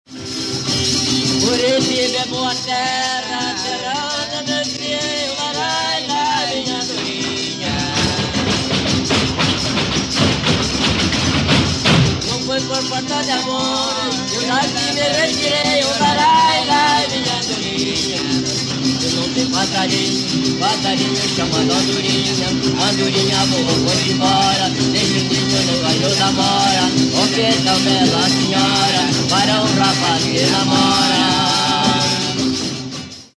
Sua coreografia consiste num sapateado vibrante, executado exclusivamente por homens calçando esporas de grandes rosetas. O bate-pé é interrompido a cada vez que os violeiros entoam uma cantiga. A dança se encerra geralmente com o "mandadinho", sob vozes de comando e trejeitos mímicos.
autor: Grupo de Fandango da Ilha dos Valadares., data: 1968